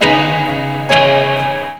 RIFFGTR 16-R.wav